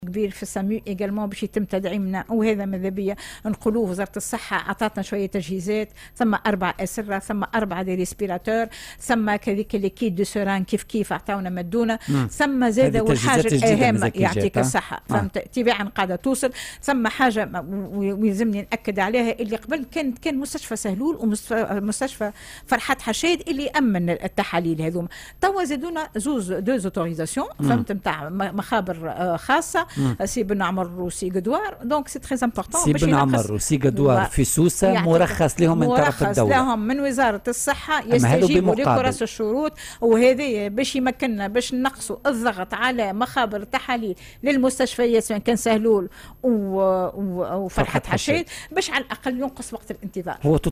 وأضافت أيضا في مداخلة لها اليوم على "الجوهرة أف أم" أنه تم الترخيص لمخبرين على ملك الخواص للقيام بتحاليل التقصي، إضافة إلى المخبرين المتواجدين بكل من مستشفى فرحات حشاد ومستشفى سهلول.